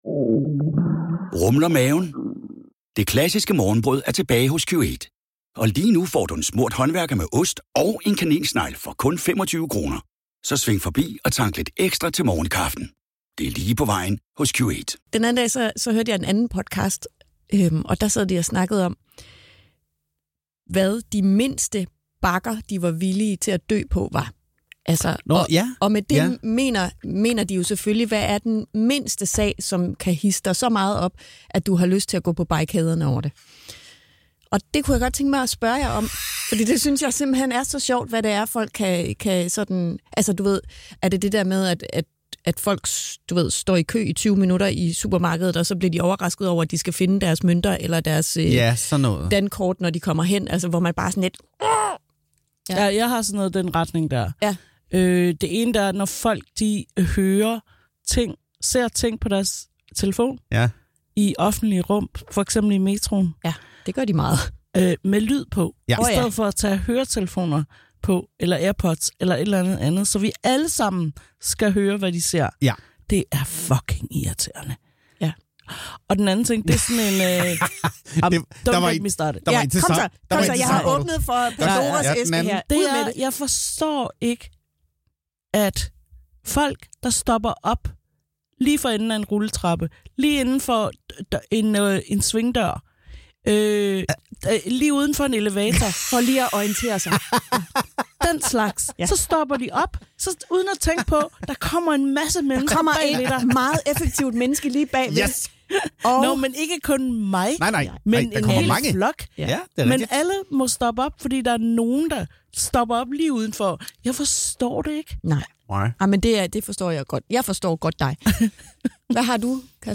What2Watch er en podcast, der hver uge udpeger de film og serier, du bare ikke må gå glip af. Bag mikrofonerne sidder tre garvede profiler: